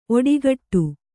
♪ oḍigaṭṭu